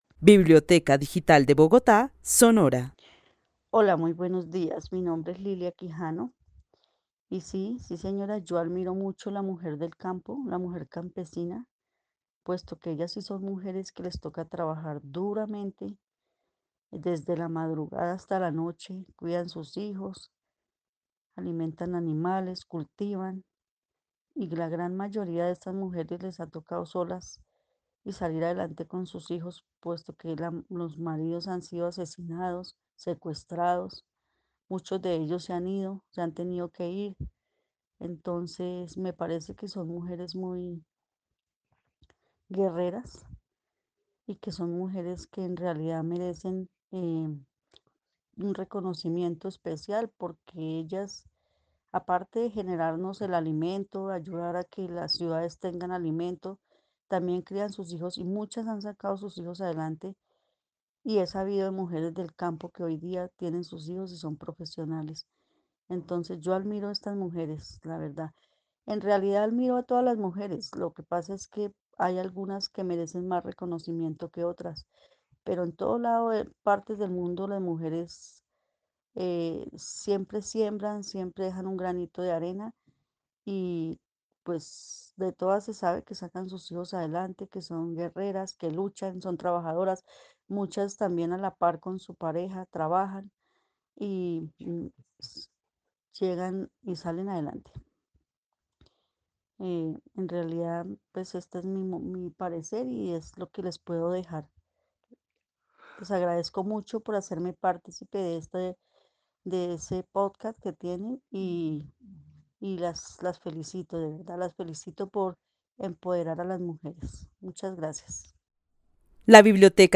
Narración oral de una mujer que vive en la ciudad de Bogotá, quien admira a las mujeres del campo porque son mujeres que asumen el trabajar duro, cuidar a sus hijos, alimentar animales y cultivar. Resalta que a la mayoría de mujeres campesinas les toca solas porque los maridos han sido asesinados o se han ido.
El testimonio fue recolectado en el marco del laboratorio de co-creación "Postales sonoras: mujeres escuchando mujeres" de la línea Cultura Digital e Innovación de la Red Distrital de Bibliotecas Públicas de Bogotá - BibloRed.